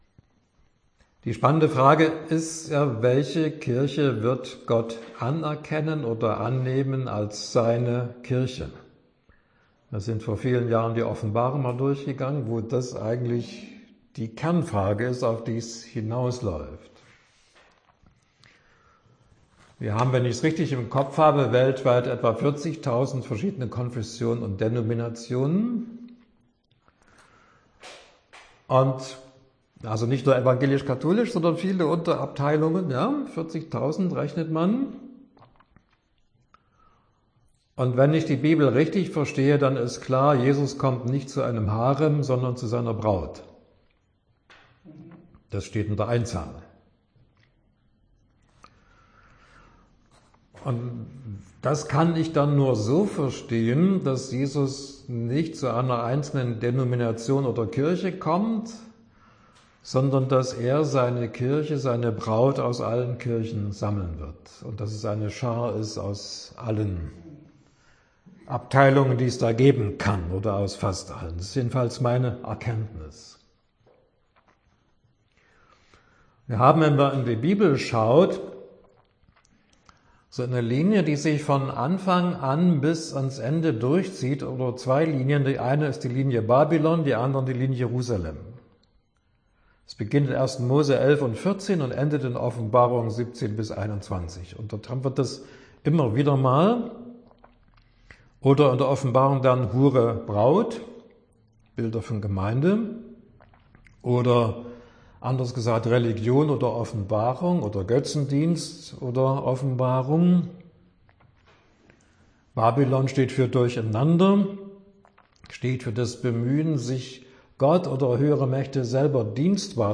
Mündliche Vorstellung der Übersetzung einer “Vision” und Anmerkungen(Dauer: ca. 50 Minuten)